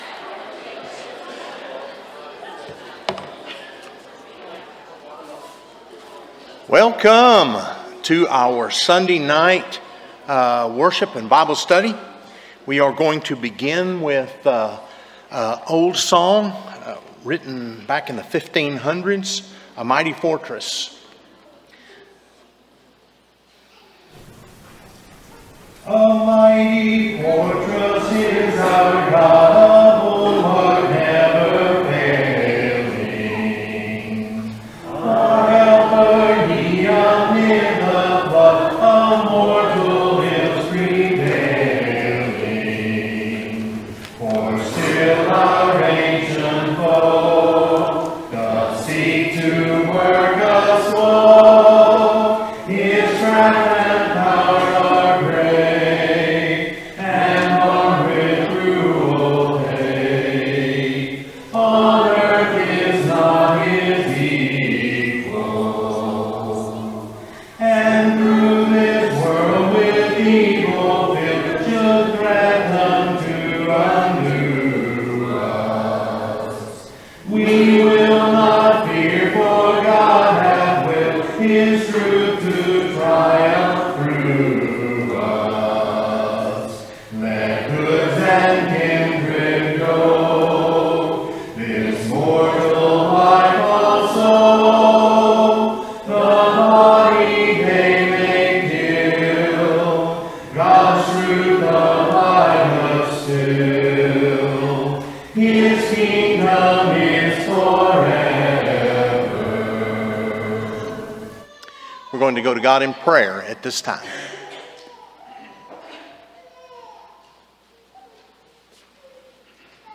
Romans 8:31, English Standard Version Series: Sunday PM Service